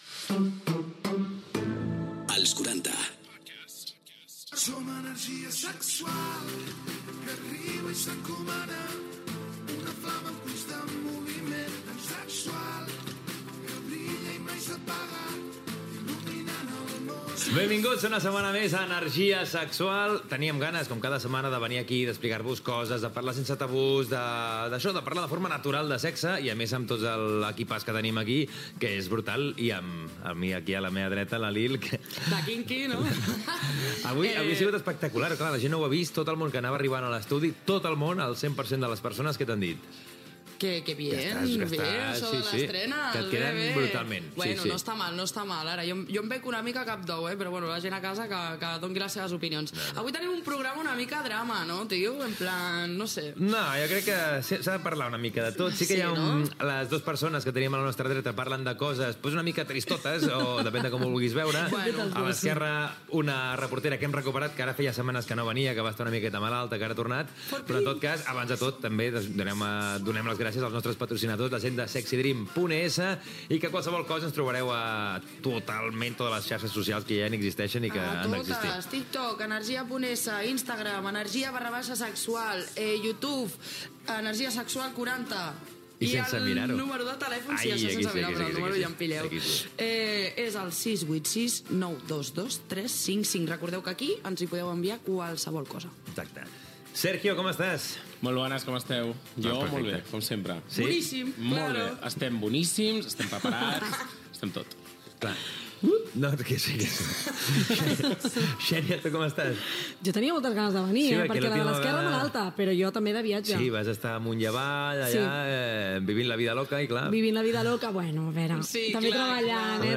Indicatiu, presentació, conversa entre l'equip, les ruptures sentimentals.
Conversa sobre la infidelitat sexual i careta de sortida
Entreteniment